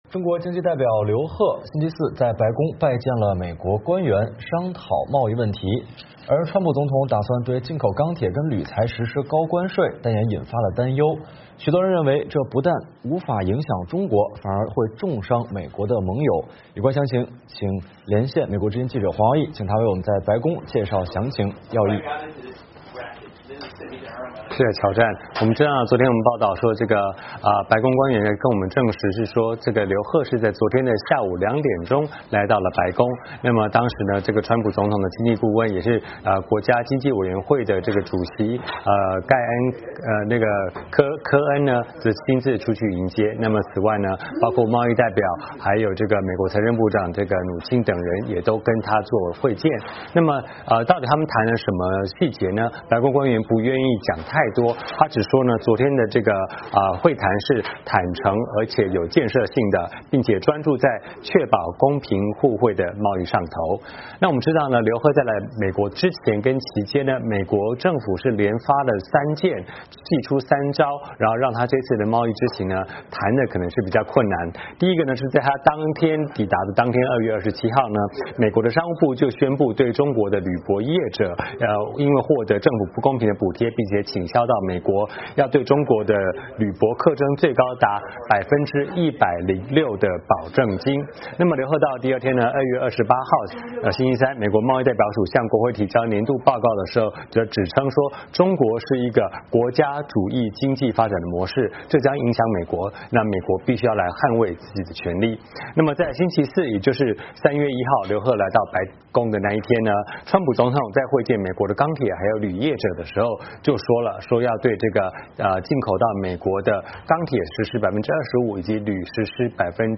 VOA连线
白宫 —